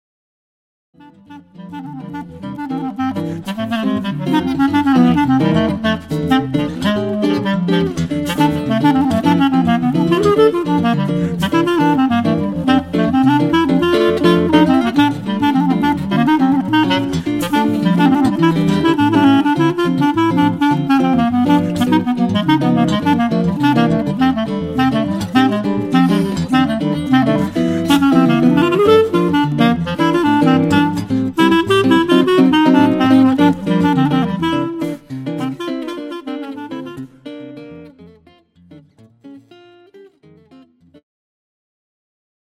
The Best In British Jazz
Recorded at Castlesound Studios, Scotland, April 2011